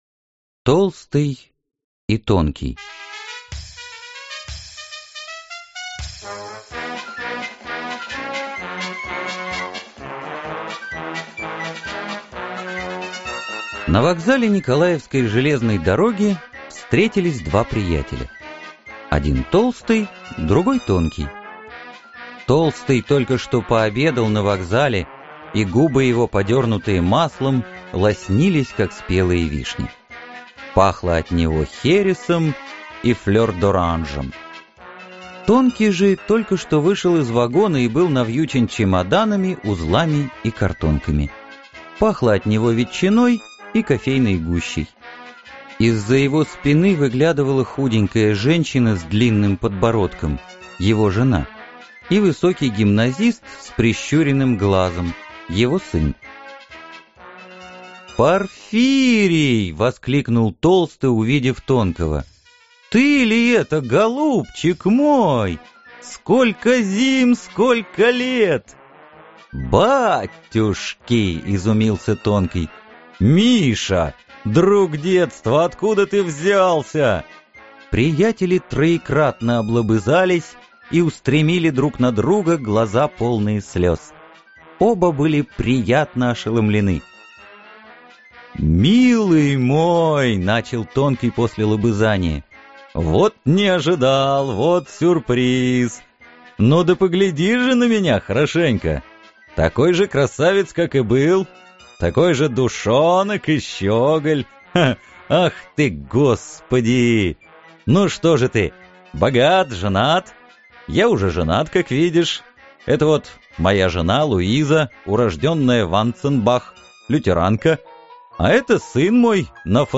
Аудиокнига Палата № 6 (сборник рассказов) | Библиотека аудиокниг